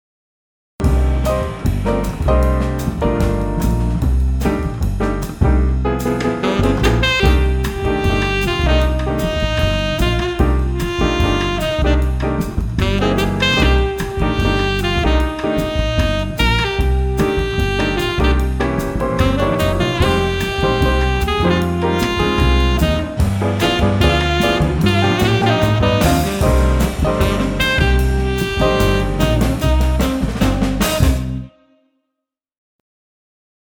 Room Effect Samples
Room_Dry.mp3